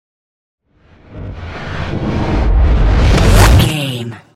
Trailer raiser flashback
Sound Effects
Fast paced
In-crescendo
Atonal
driving
futuristic
tension
dramatic
riser